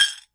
Coin Currency Sounds » Large Coin Into Pile Of Coins 1
描述：A large coin being dropped into a pile of coins. Recorded as a 16bit stereo WAV file.
标签： coin dropping currency drop money coins
声道立体声